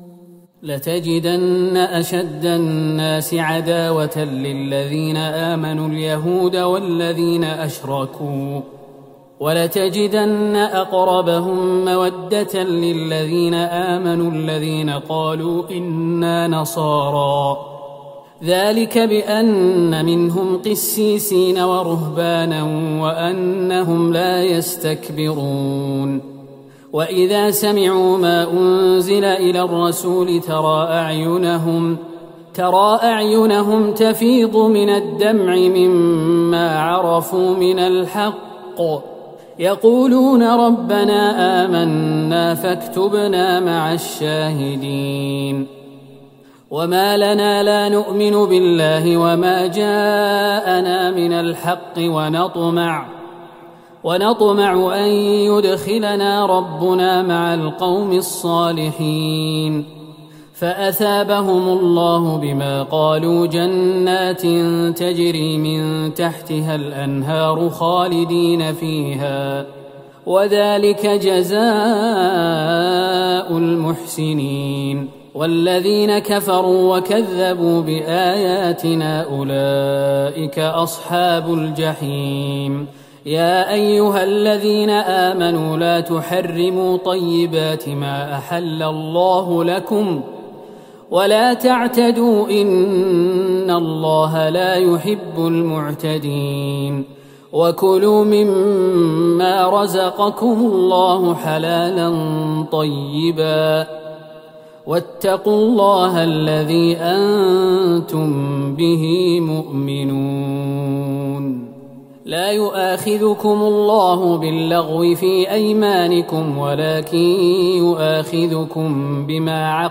ليلة ٩ رمضان ١٤٤١هـ من سورة المائدة { ٨٢-١٢٠ } والأنعام { ١-٢٠ } > تراويح الحرم النبوي عام 1441 🕌 > التراويح - تلاوات الحرمين